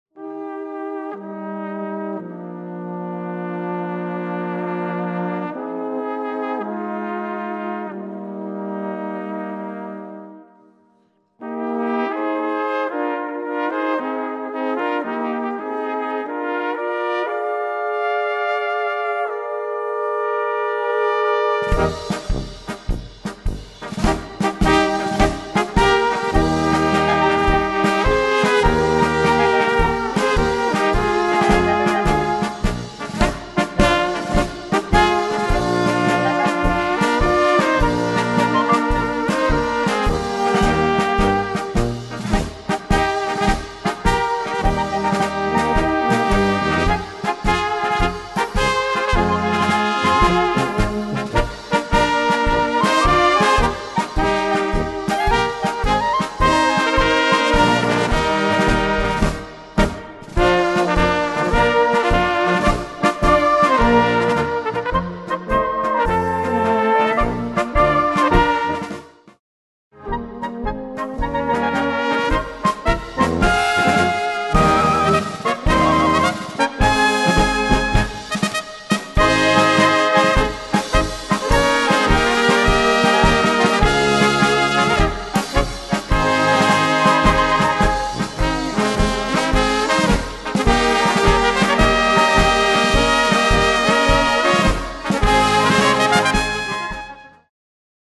Gattung: Polka
Besetzung: Blasorchester
Schwungvolle Polka